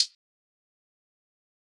Metro Low Hihat.wav